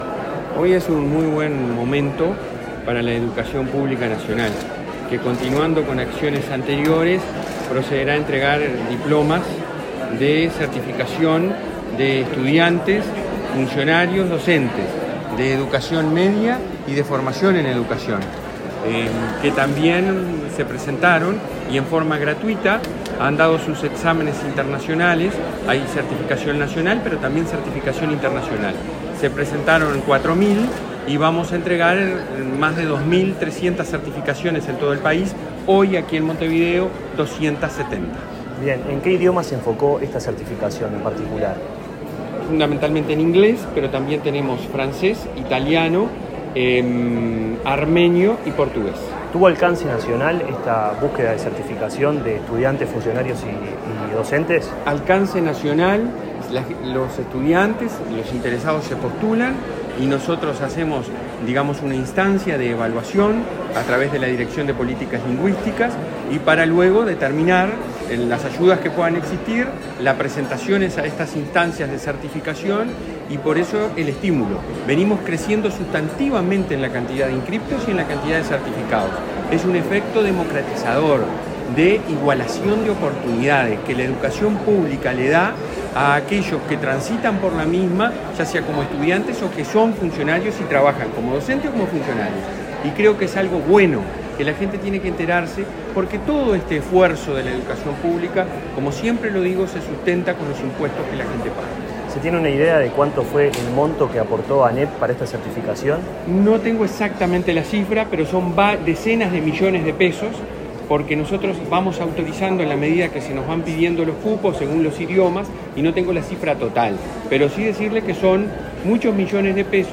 Entrevista al presidente de ANEP, Robert Silva
El presidente de la ANEP, Robert Silva, dialogó con Comunicación Presidencial, antes del acto de entrega de certificados de los cursos de lenguas